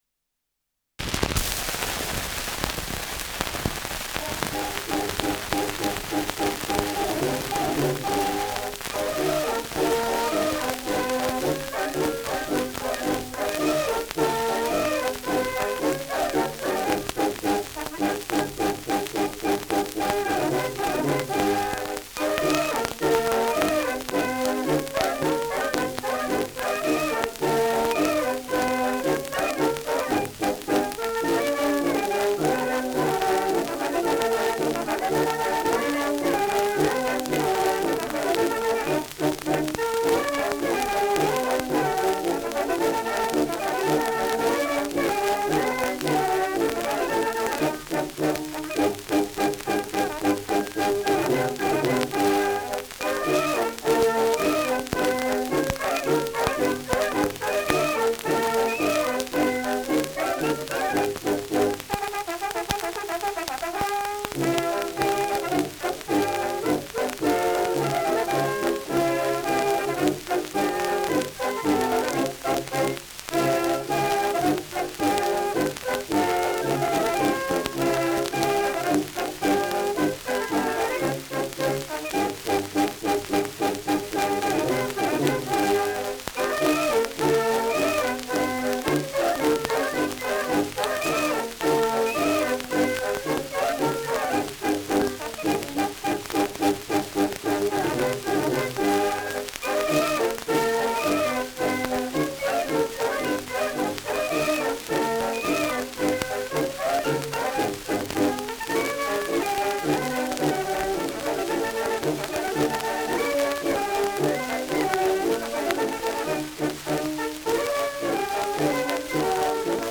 Schellackplatte
Starkes Grundrauschen : Gelegentlich leichtes bis stärkeres Knacken